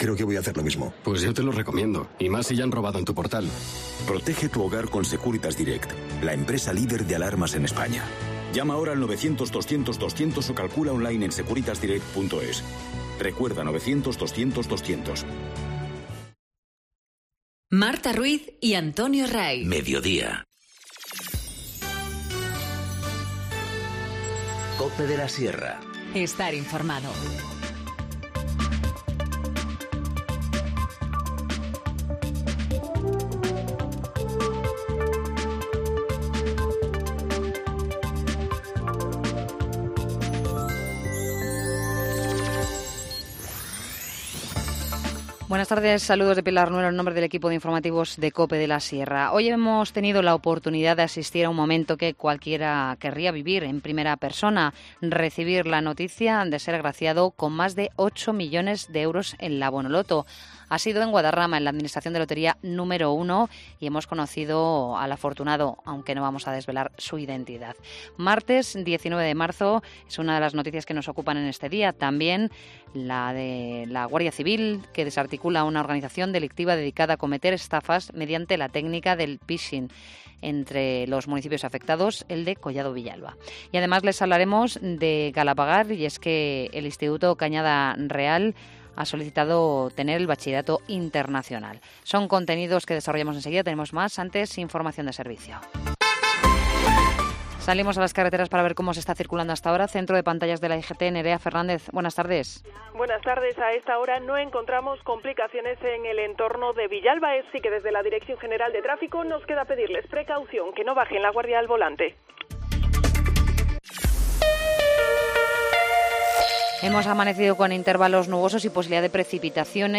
Informativo Mediodía 19 marzo 14:20h